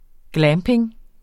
Udtale [ ˈglæːmpeŋ ]